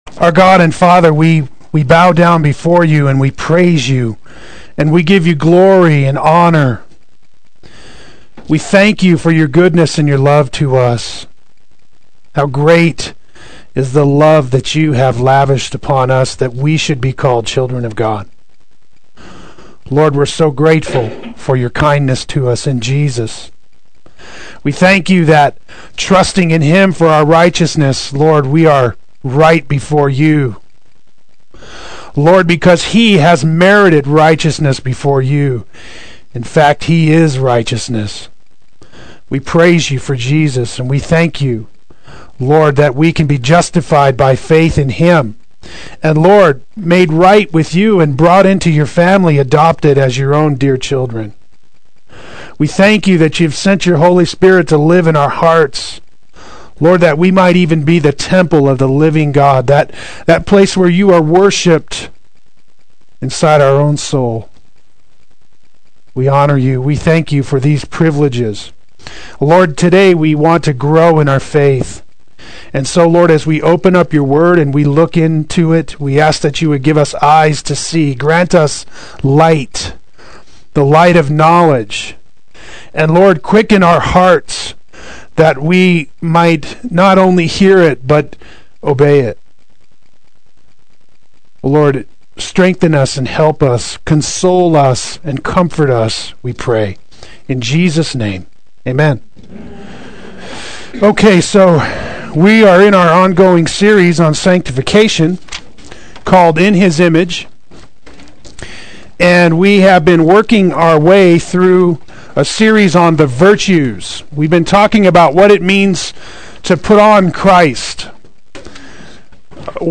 Play Sermon Get HCF Teaching Automatically.
Personal Faith and Treasuring Christ Adult Sunday School